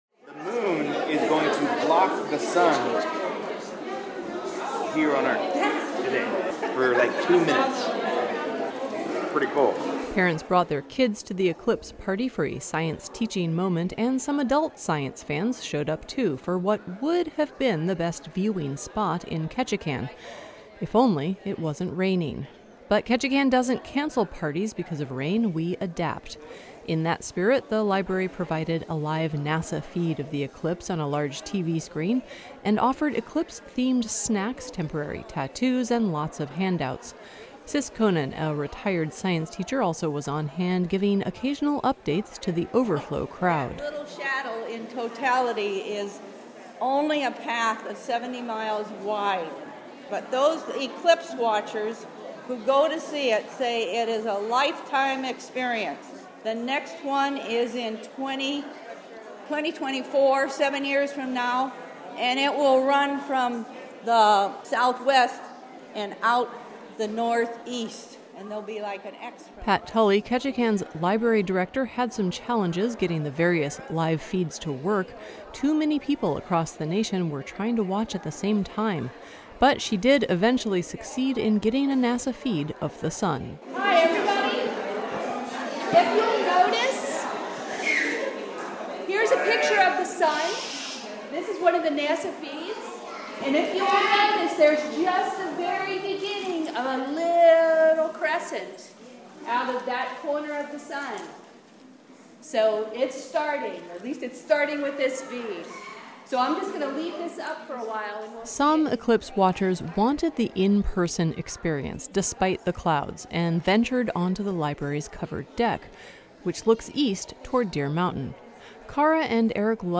Ketchikan experienced a partial solar eclipse on Monday, but it was difficult to tell because of a dense cloud cover and heavy drizzle. Despite the atmospheric limitations, about 200 people showed up at the Ketchikan Public Library for an eclipse viewing party.
While we were out on the deck, there was a smattering of applause from inside, where people watched the live feed.